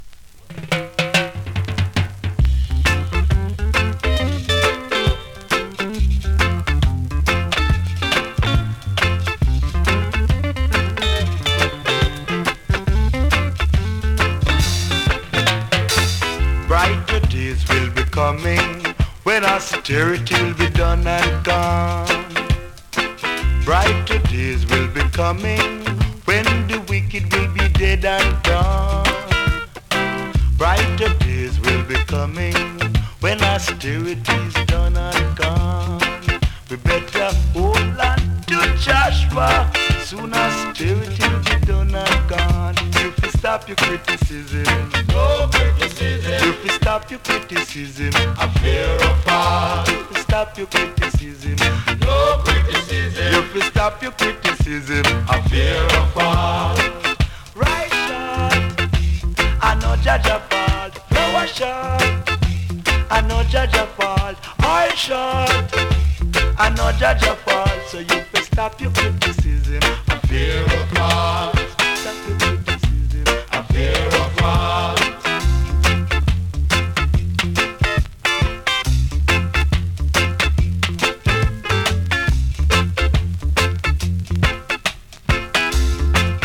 SKA〜REGGAE
スリキズ、ノイズ比較的少なめで